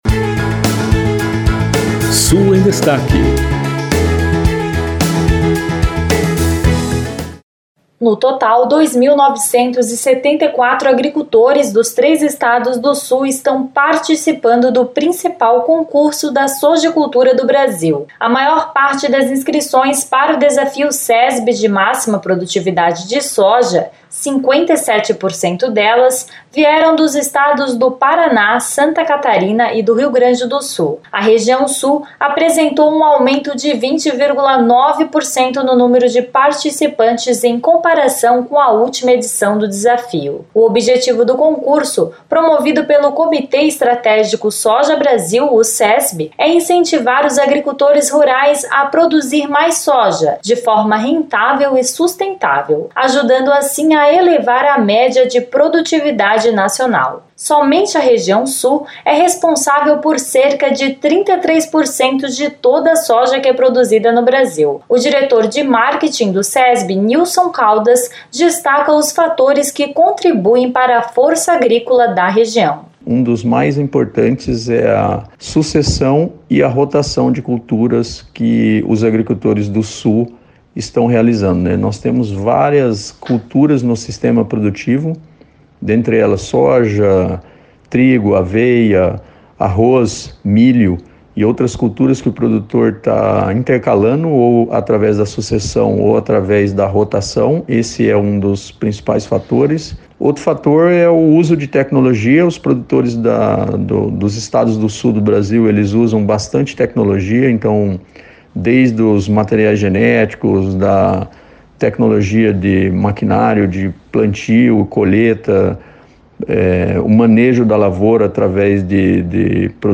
De Florianópolis